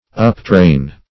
Uptrain \Up*train"\, v. t.